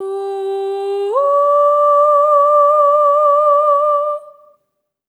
SOP5TH G4 -L.wav